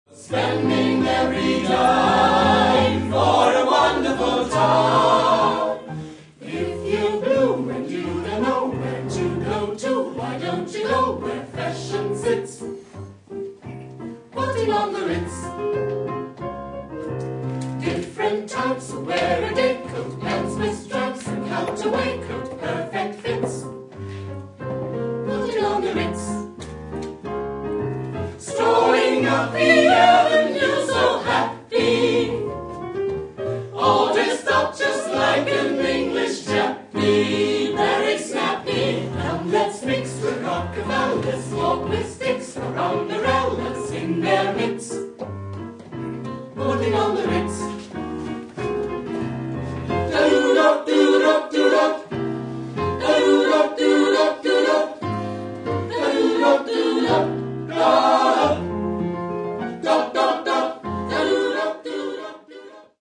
Junge Menschen singen von einer Zeit, von der sie keine Ahnung haben